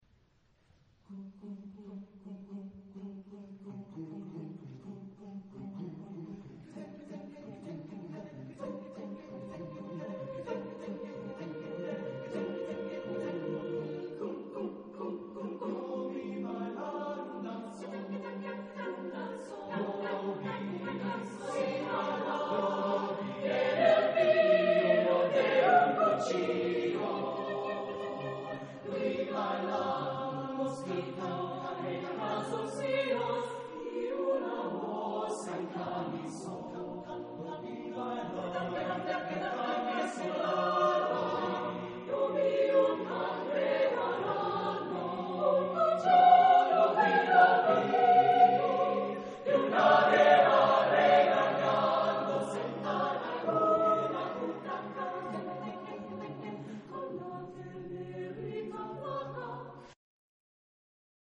Genre-Style-Form: Folk music
Type of Choir: SSAATTBB  (8 mixed voices )
Tonality: C minor